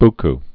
(bk, byky)